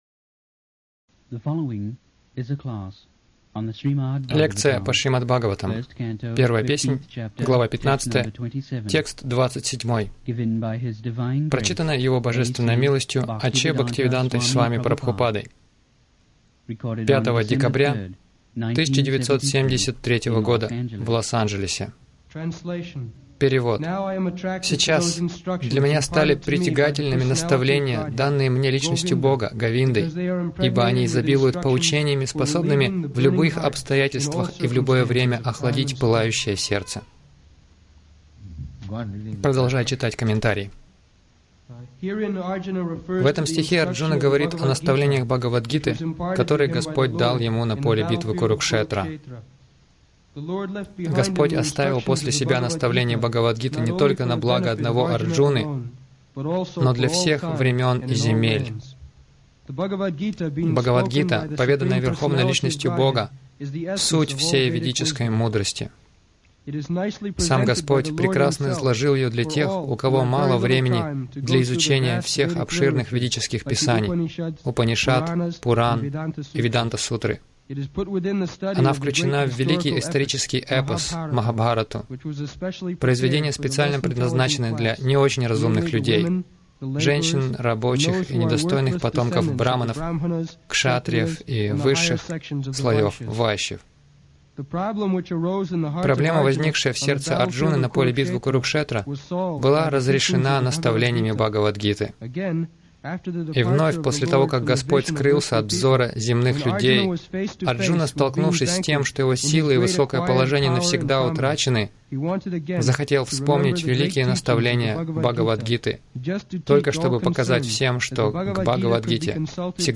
Милость Прабхупады Аудиолекции и книги 05.12.1973 Шримад Бхагаватам | Лос-Анджелес ШБ 01.15.27 — Страдания ради наслаждения Загрузка...